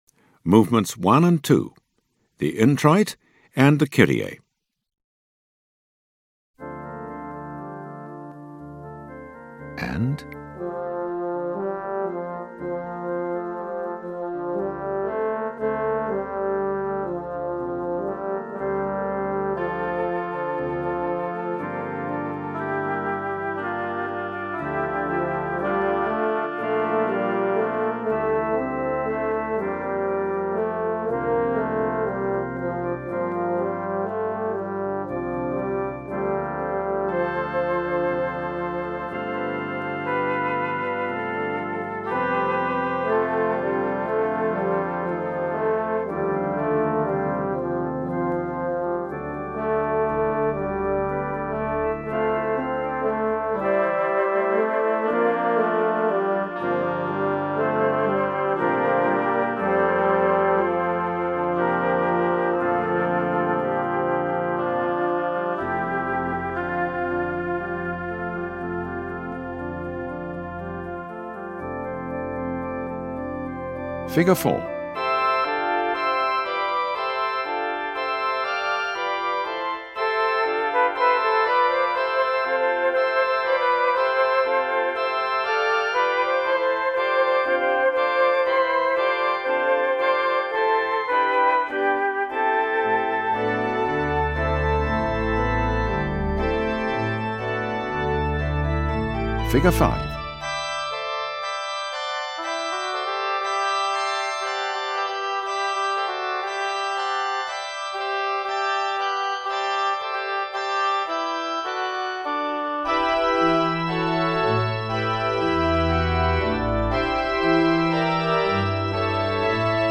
There is no text, just your part.
1st Tenor